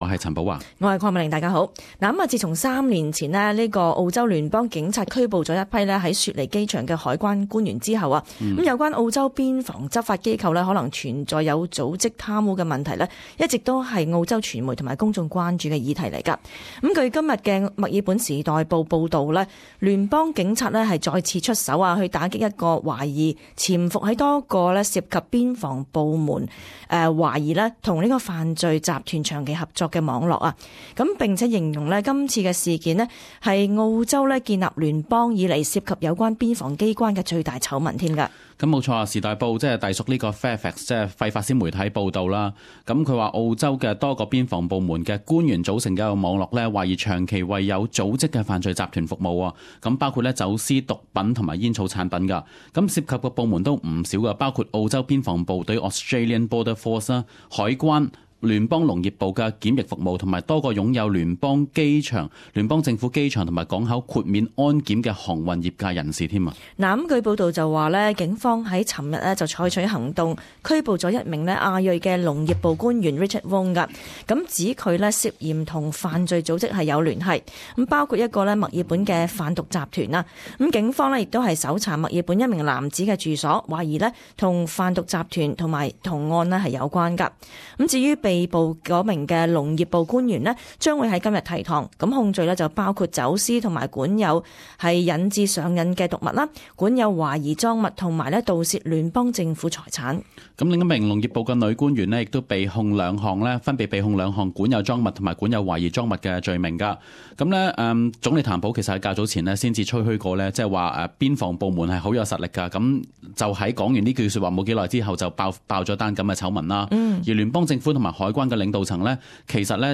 時事報導: 邊防官員疑助走私毒品煙草